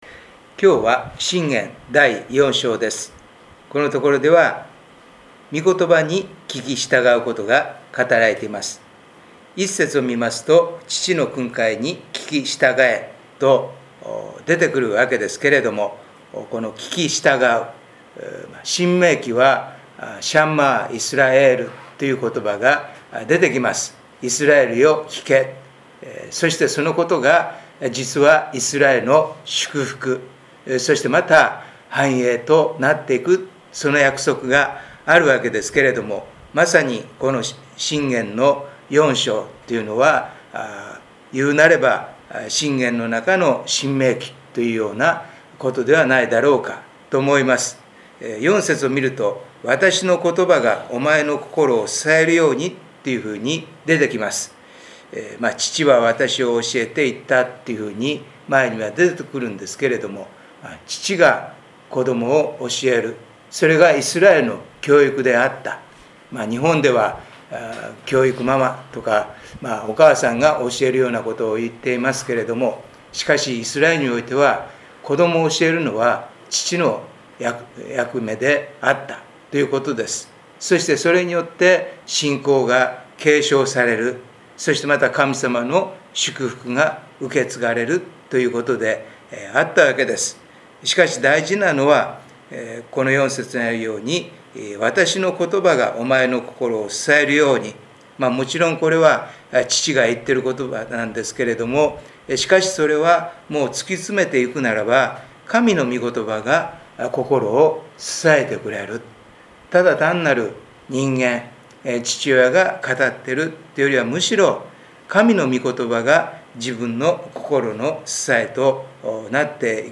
礼拝メッセージ「新しいいのちに生きる」│日本イエス・キリスト教団 柏 原 教 会